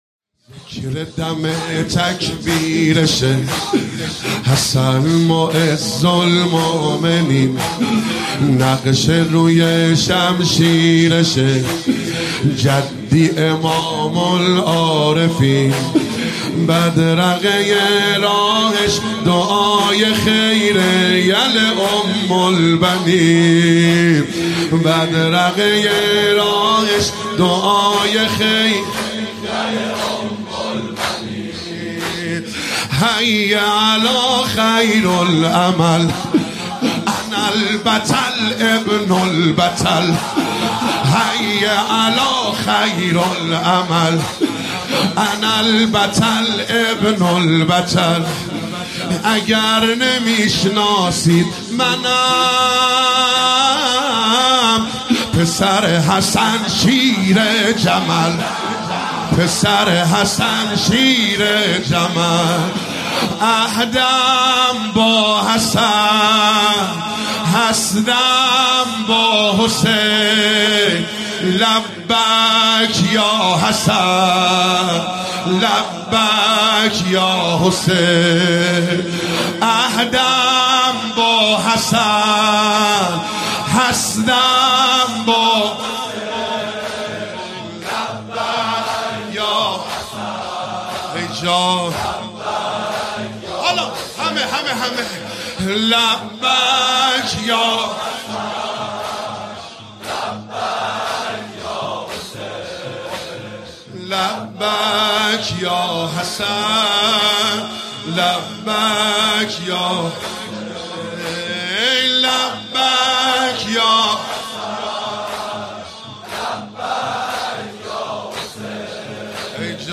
شب ششم محرم97 هیئت یا فاطمه الزهرا (س) بابل